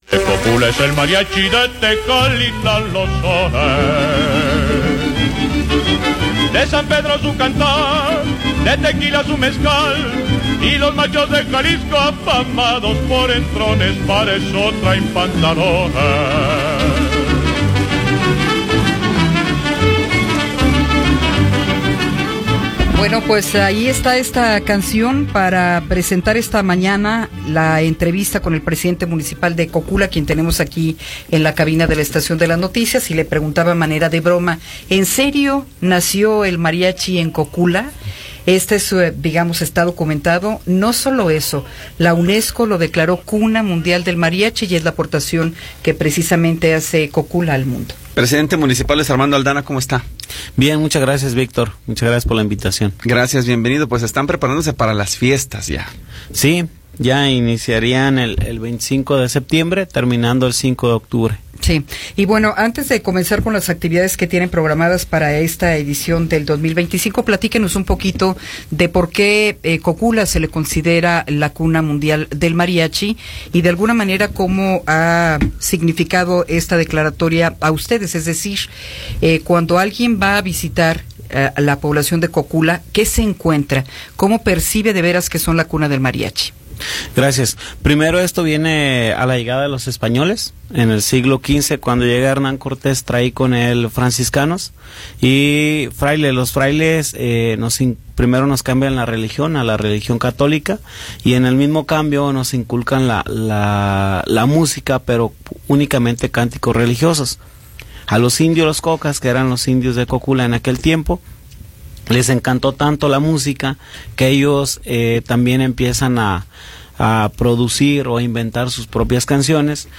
Entrevista con Luis Armando Aldana González